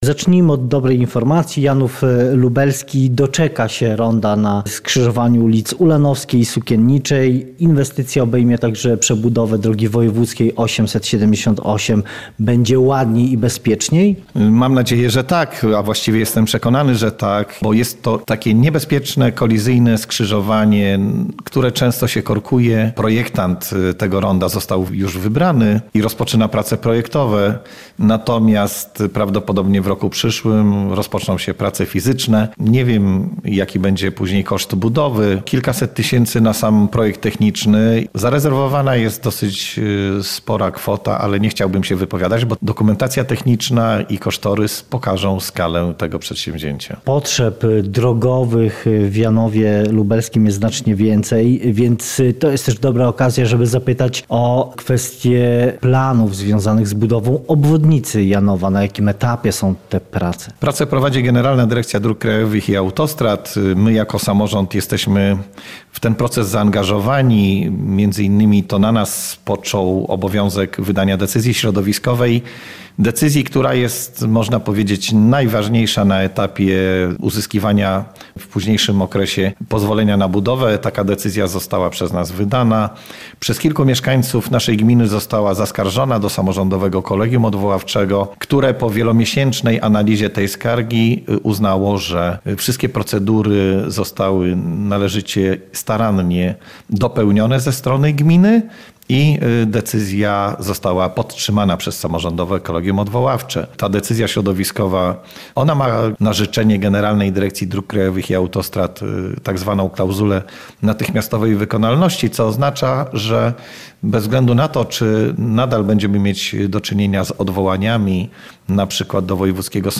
Cała rozmowa w materiale audio: